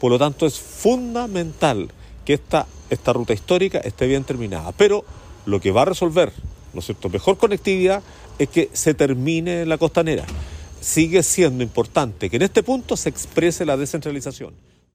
El alcalde Antonio Rivas, destacó la importancia de la ruta, para la conectividad de Chiguayante y las comunas aledañas.
cuna-camino-alcalde.mp3